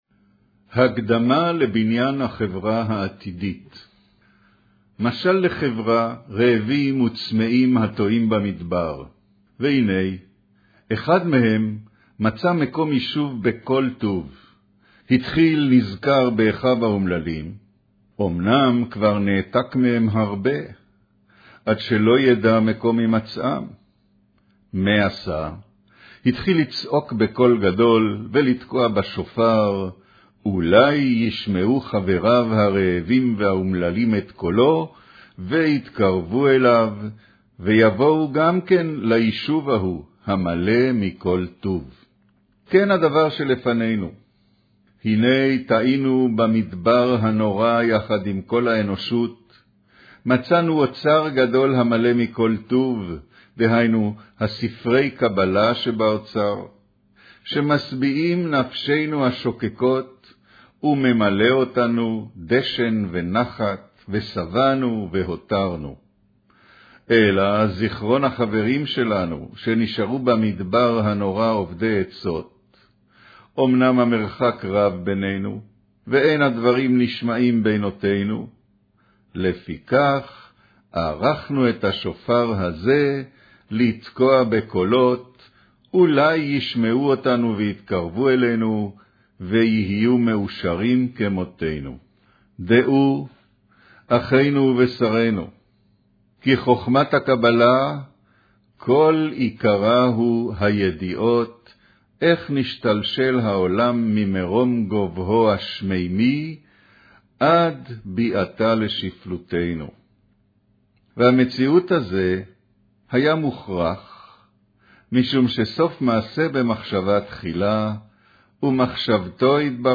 אודיו - קריינות הקדמה לבניין החברה העתידית